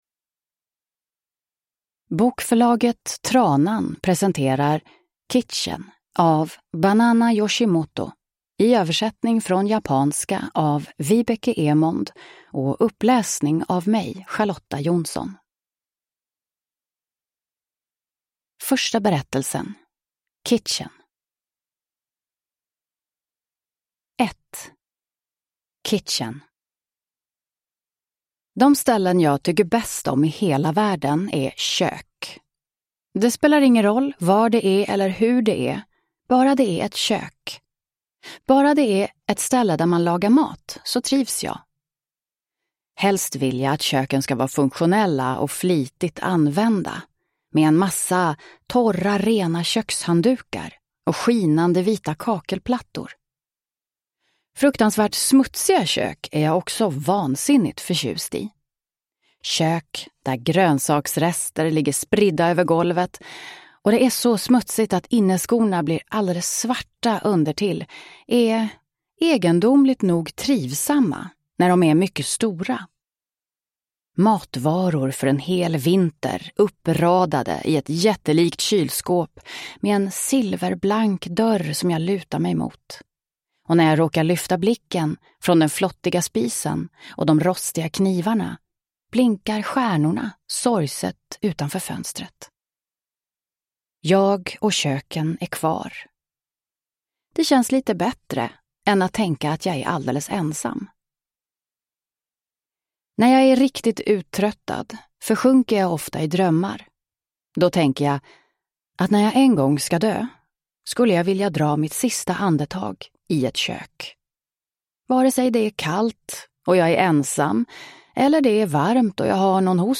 Kitchen – Ljudbok